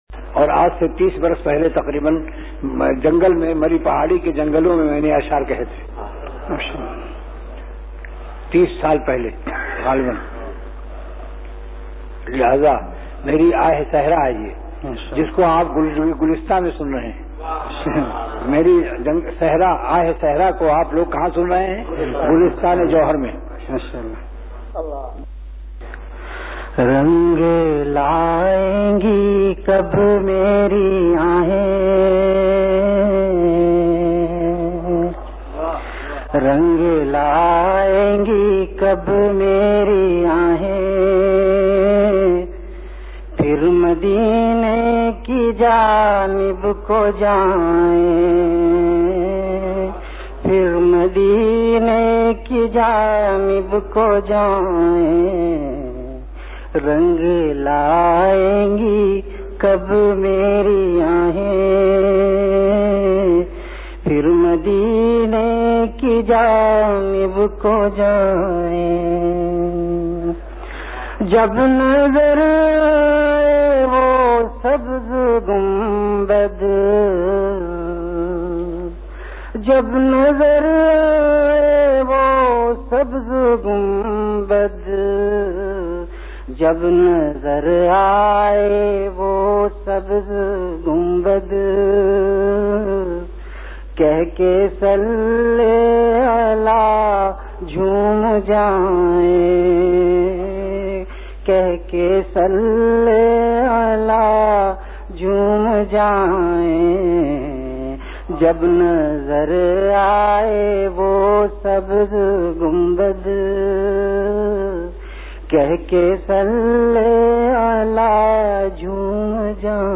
Delivered at Khanqah Imdadia Ashrafia.
Ashaar · Khanqah Imdadia Ashrafia